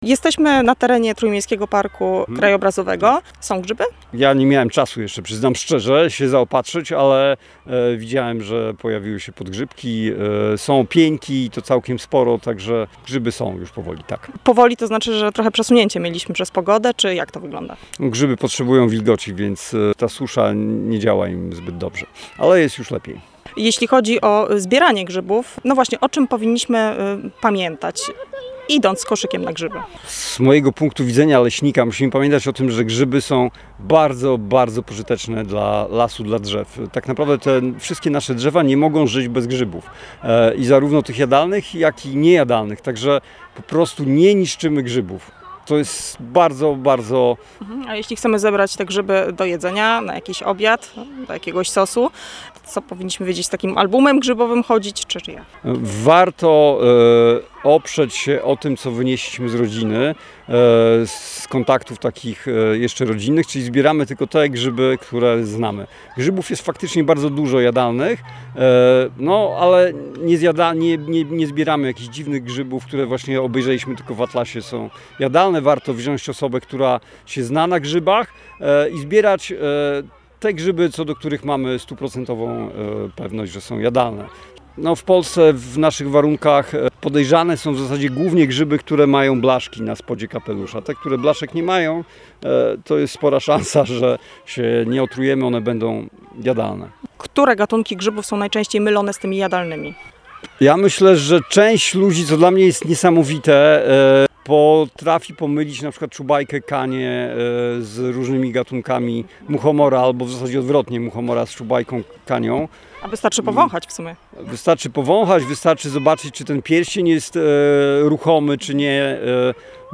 Jak łatwo je odróżnić? Tłumaczy ekspert [POSŁUCHAJ]